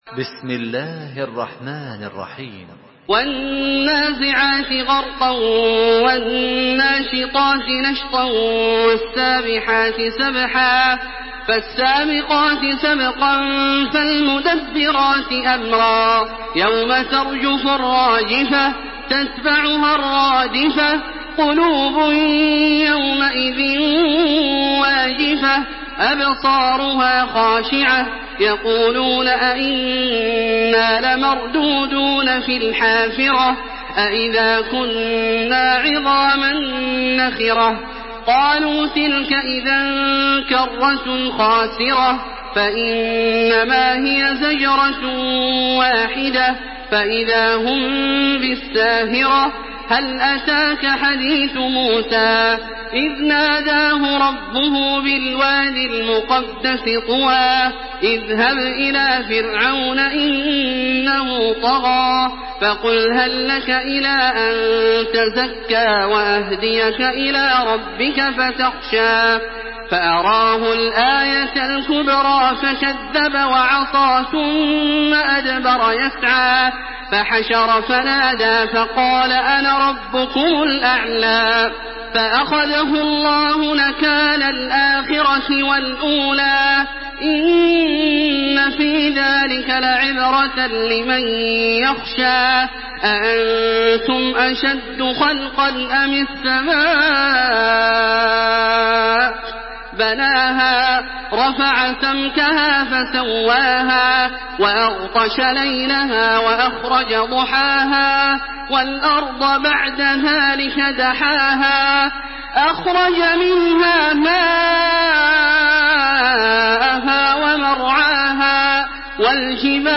تحميل سورة النازعات بصوت تراويح الحرم المكي 1427
مرتل